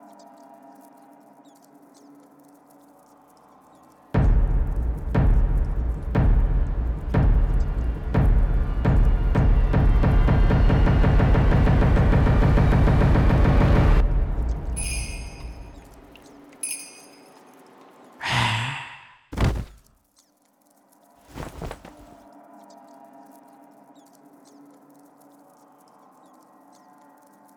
sfx_生死簿演出.wav